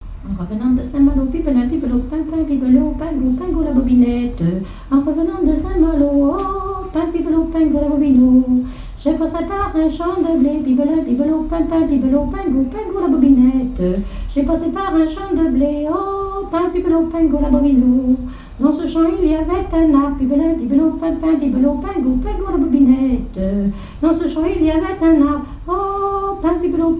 Genre laisse
airs de danses et chansons traditionnelles
Pièce musicale inédite